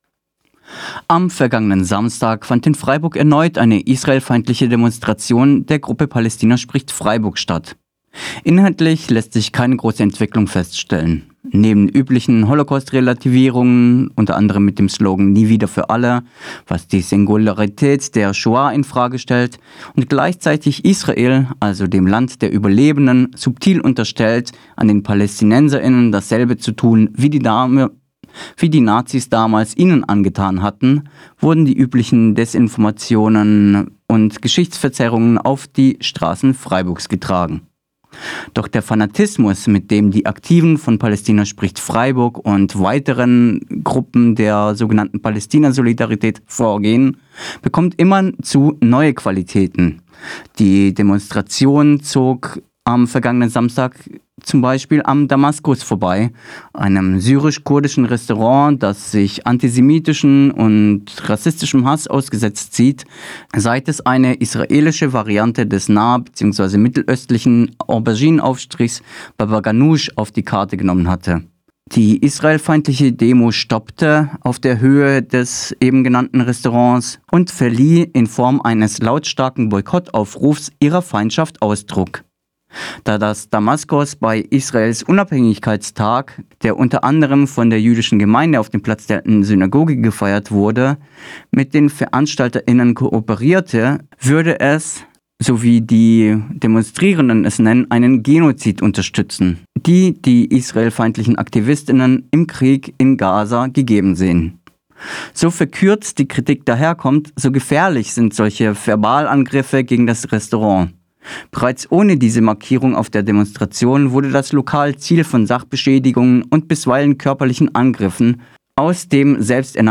Rede des Kurdischen Frauenrats: 2:11